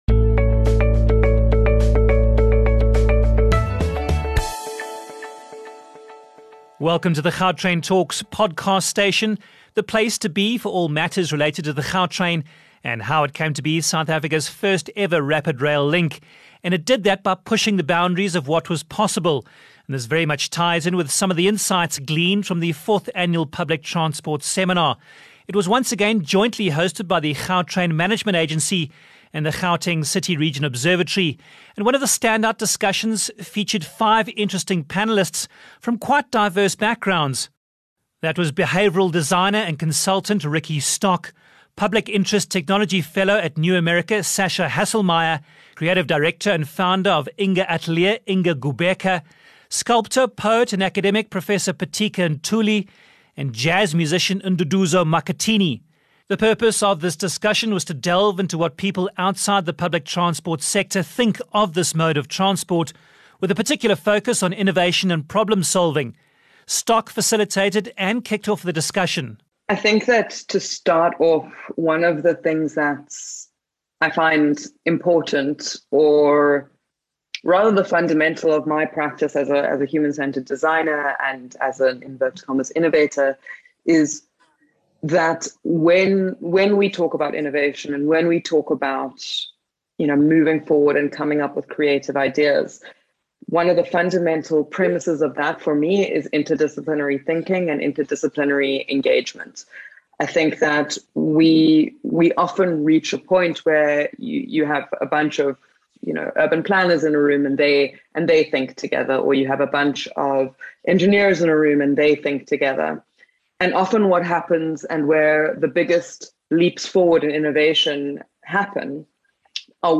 12 Jul Panel Discussion – Public Transport Seminar
The fourth annual Public Transport Seminar was jointly hosted by the Gautrain Management Agency and the Gauteng City Region Observatory, and one of the standout discussions featured five interesting panellists from quite diverse backgrounds.